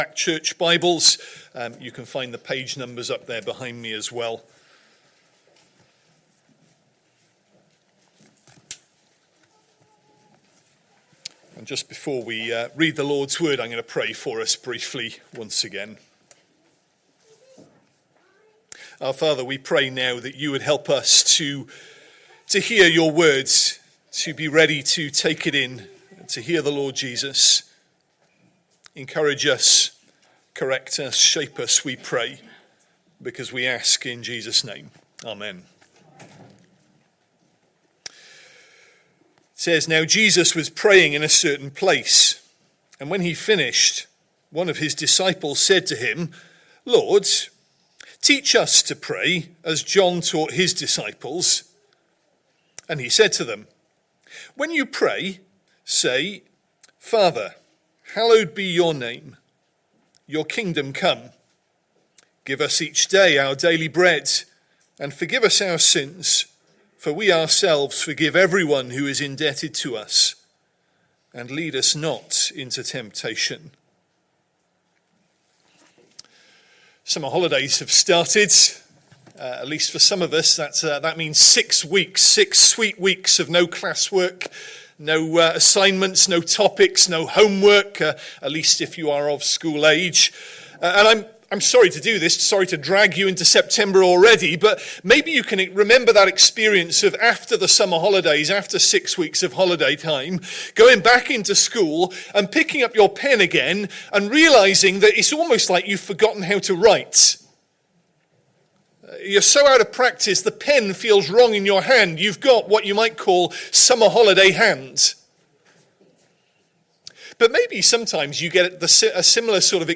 Message: “Luke 24”
Sunday AM Service Sunday 2nd November 2025 Speaker